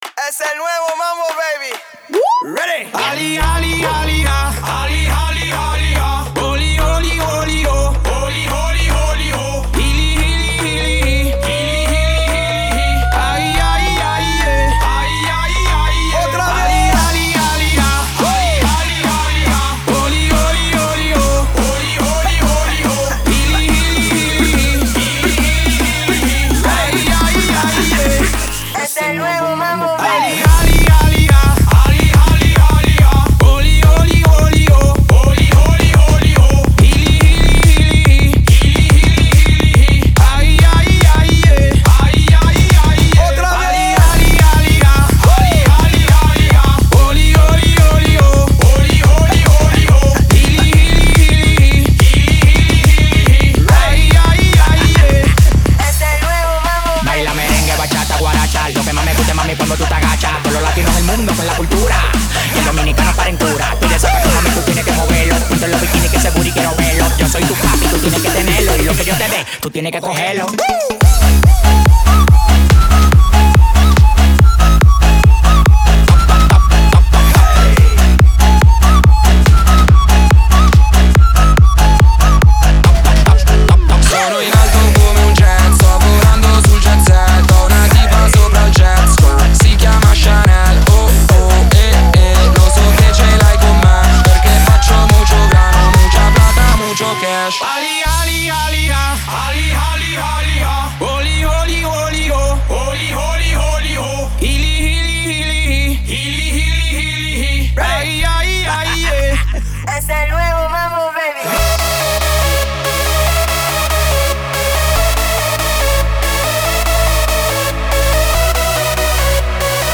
это зажигательный трек в жанре EDM и реггетон